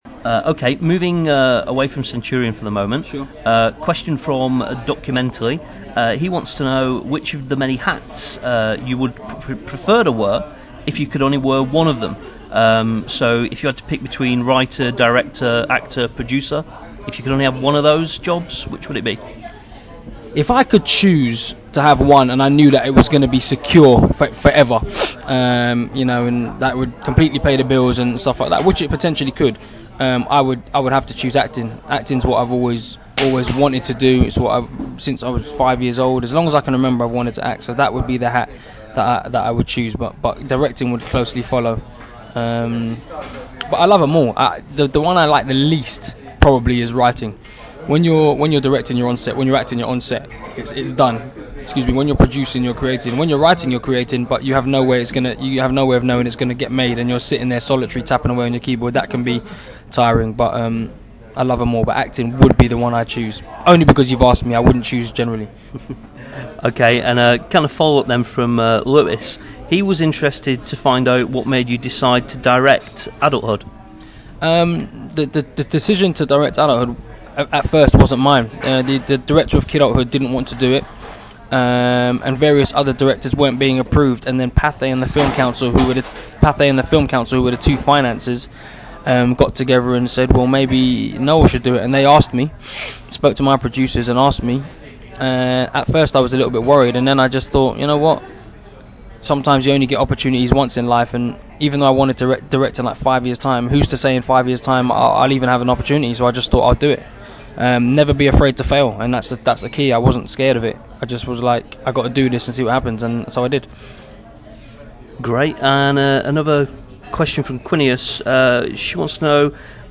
Noel Clarke Interview Part Three
5026-noel-clarke-interview-part-three.mp3